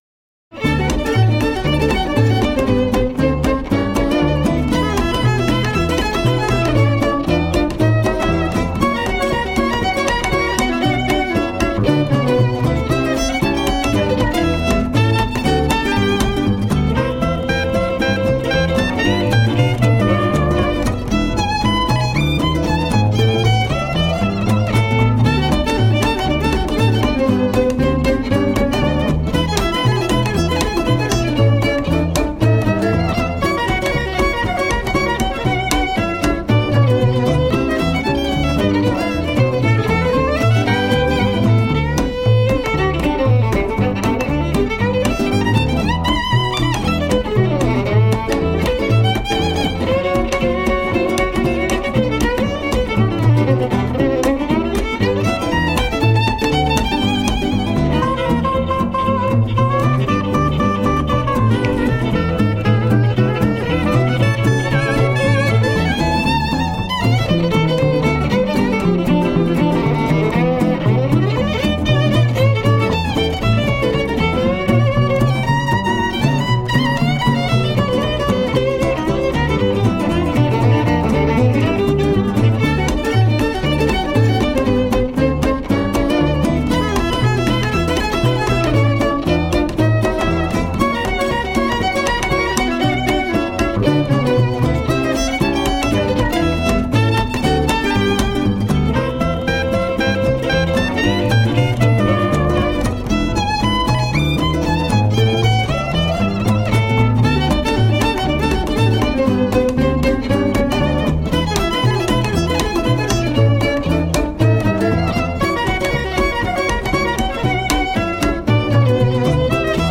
Violin
Clarinet, Bass Clarinet, Soprano sax
Guitar
Double bass.
Tagged as: Jazz, Blues, Instrumental Jazz